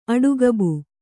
♪ aḍugabu